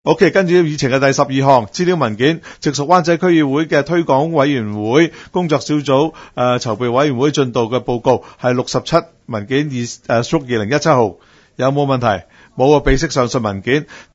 区议会大会的录音记录
湾仔区议会第十二次会议